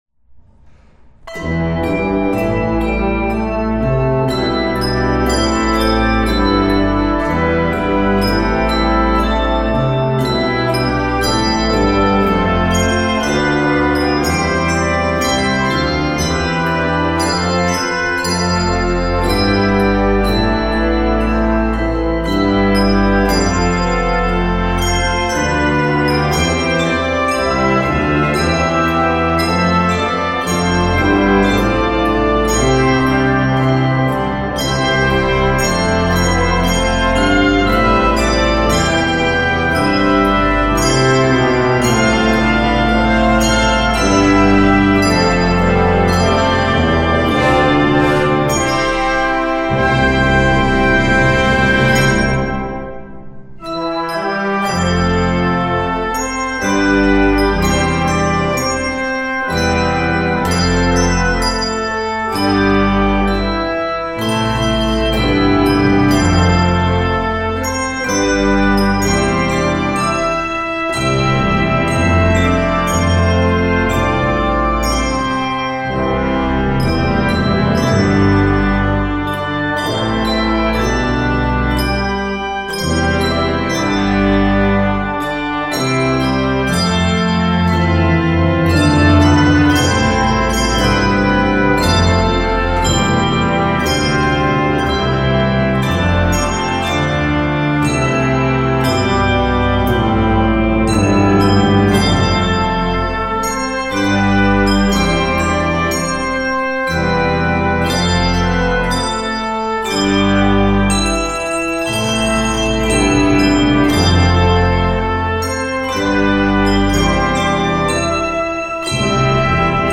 Key of C Major.